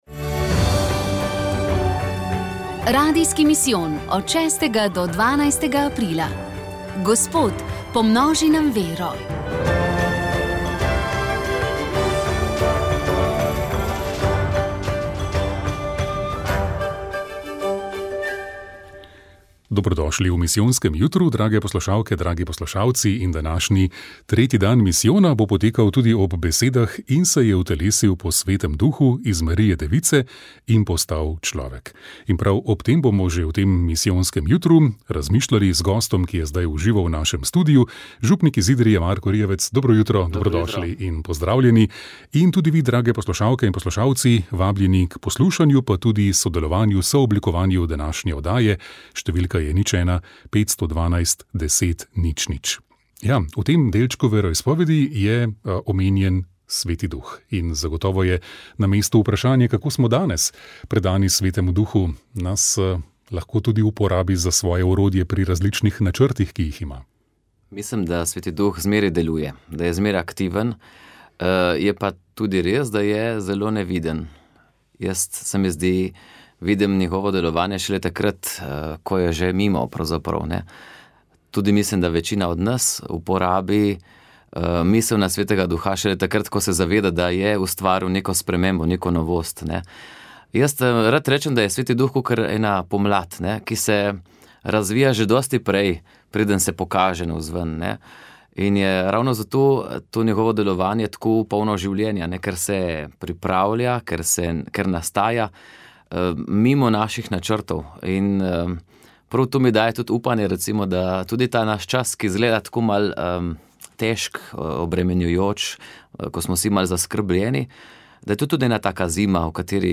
1. dan: Večerni pogovor z ljubljanskim nadškofom Stanislavom Zoretom
V župnijski dvorani župnije Ljubljana - Koseze je bil pogovorni večer z ljubljanskim nadškofom Stanislavom Zoretom. Večere v tednu Radijskega misijona smo naslovili Vprašajte škofa.